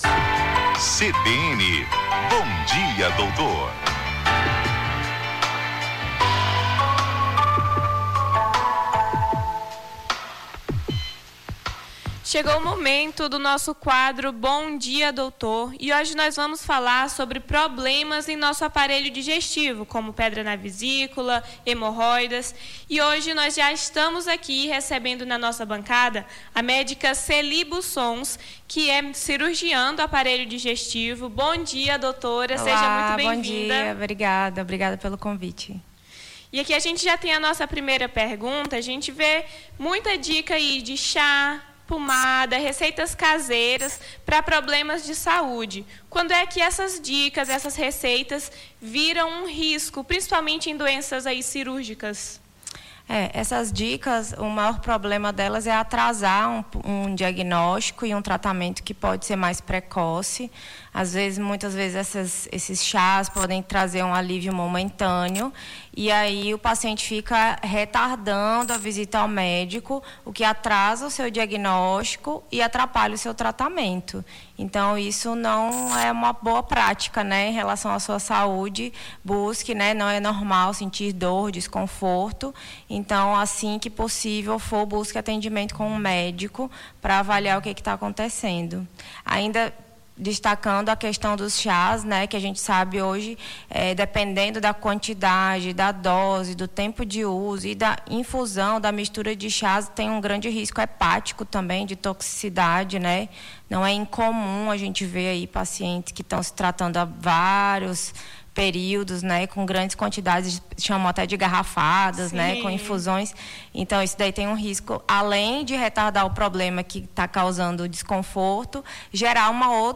conversamos com a médica